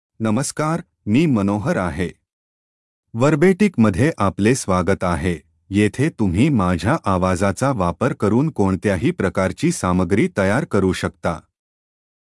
MaleMarathi (India)
Manohar — Male Marathi AI voice
Voice sample
Manohar delivers clear pronunciation with authentic India Marathi intonation, making your content sound professionally produced.